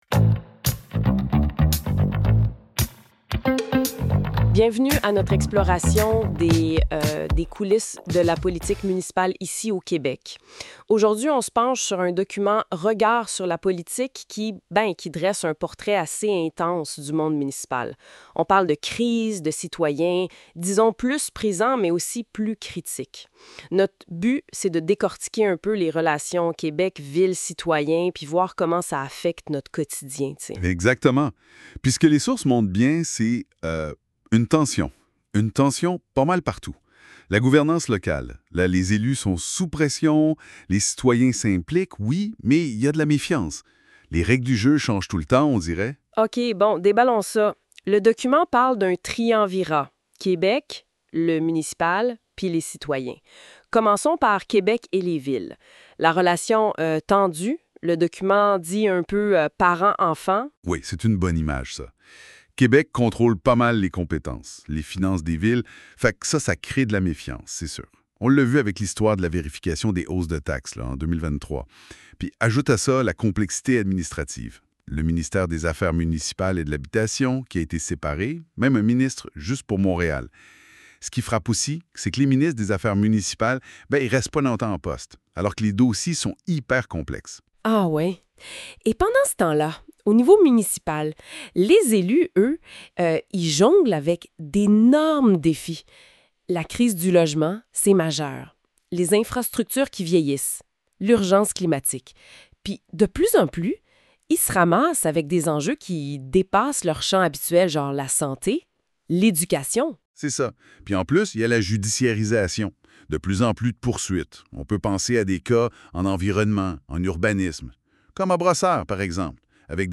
Ce podcast est généré par intelligence articifielle